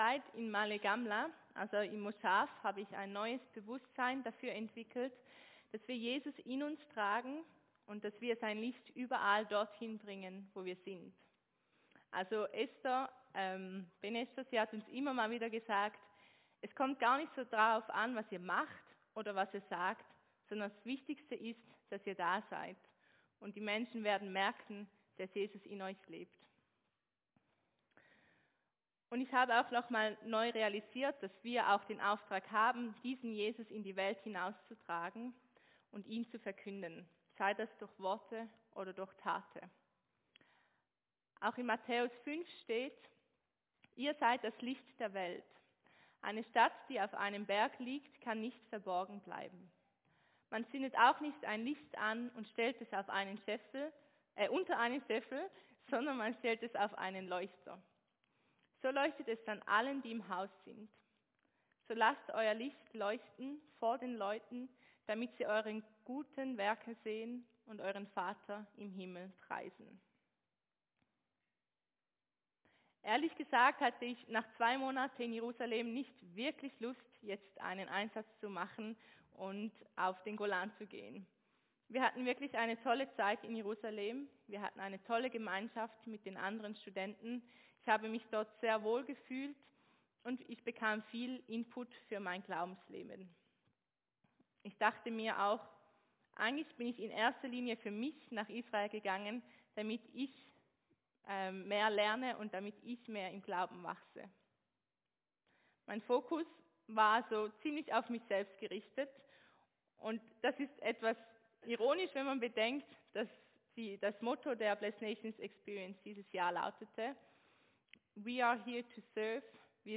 Predigt-14.4.24.mp3